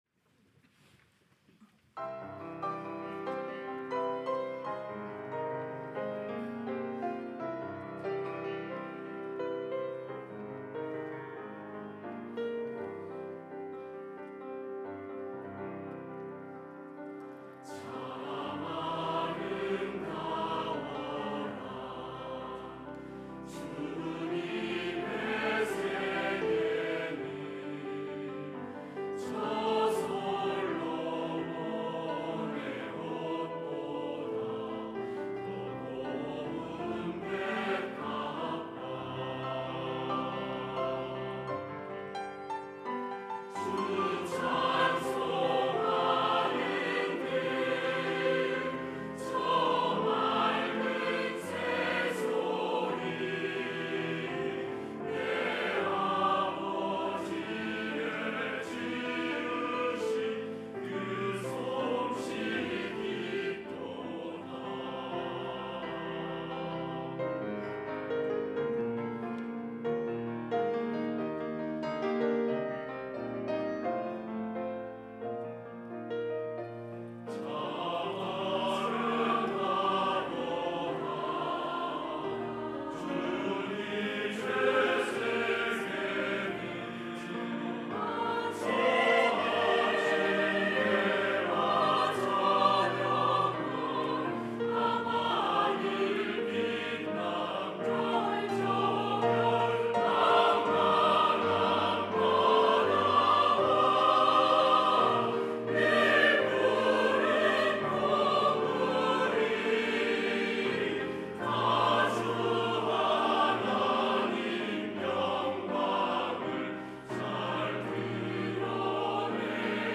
할렐루야(주일2부) - 참 아름다워라
찬양대